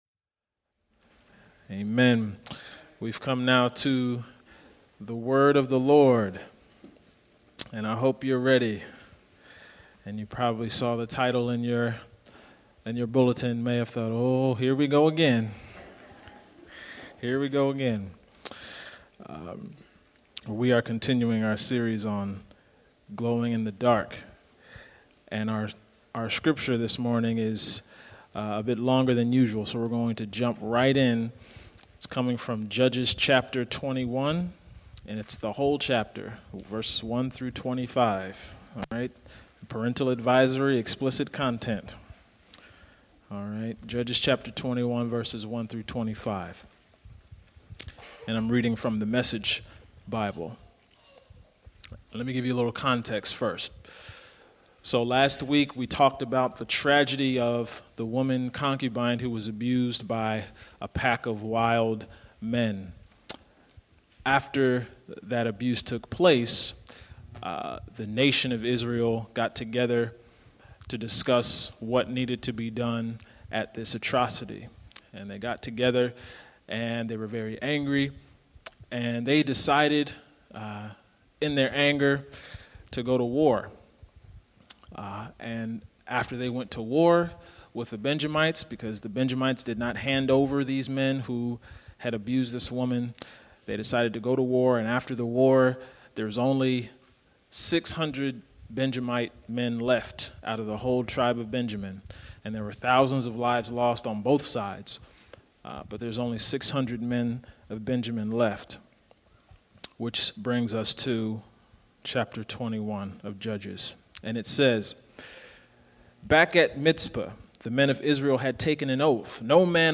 HBC+Sermon+November+1,+2015.mp3